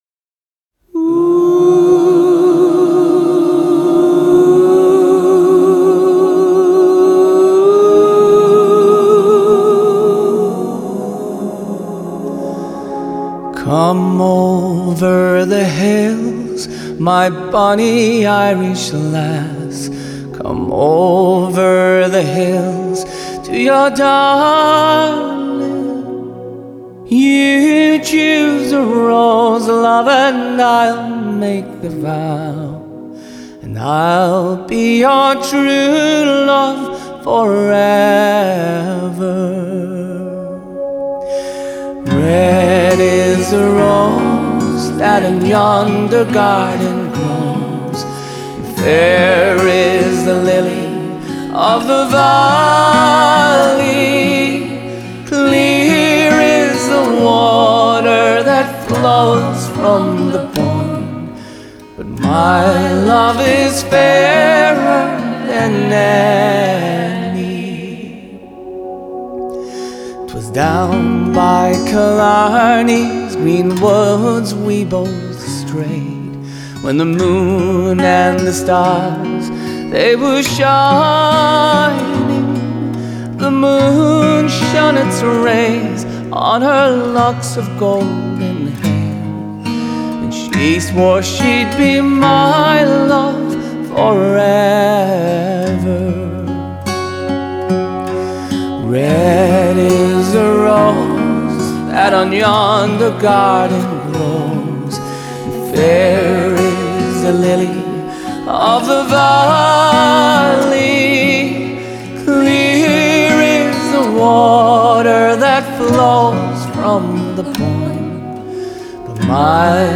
Genre: Folk/Country/Pop